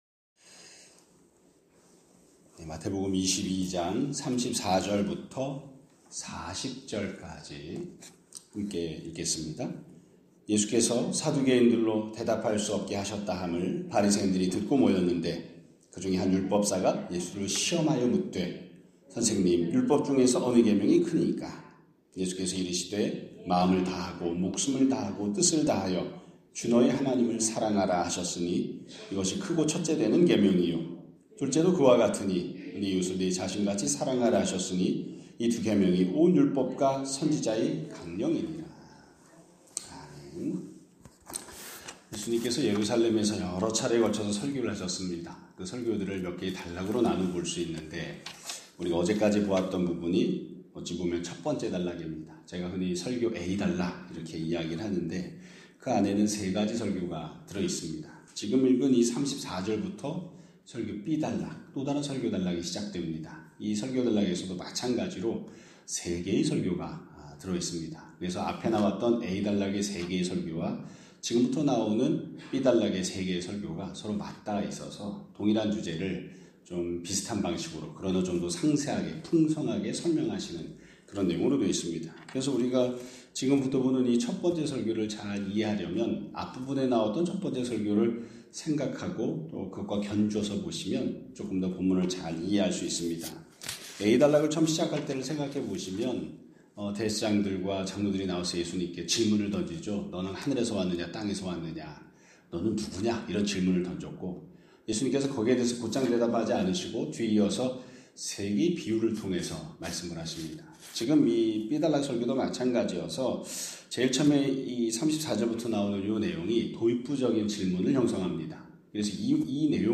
2026년 2월 12일 (목요일) <아침예배> 설교입니다.